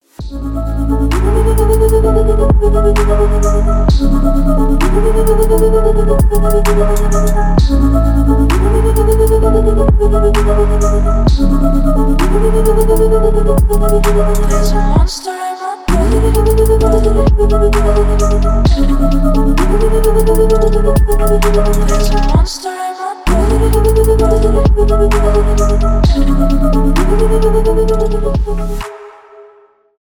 electronic , атмосферные